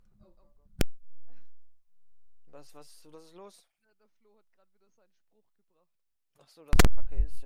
Knacken im Recording / Stream / Voice
Es hört sich an, wie wenn man mit der Klinke die Buchse verfehlt oder ein Klinkenstecker schlechten Kontakt hat (siehe Testsample im Anhang).
Treiber des Focusrite neuinstalliert, USB-Buchse wie auch -kabel gewechselt, etc. pp. Momentan im Betrieb: Rode NT-1A Focusrite Scarlett 2i2 3rd Gen CORDIAL XLR Mikrofonkabel 3 m Rode PSA1 (Mikrofonarm mit Tischbefestigung) Vorher in Verwendung: Rode NT-1A (auch ausgetauscht -> nicht das Gleiche wie oben!)
Eigenschaft Anhänge Testsample Störgeräusch.mp3 79,4 KB · Aufrufe: 393